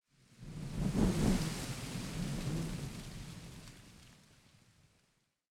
housewind05.ogg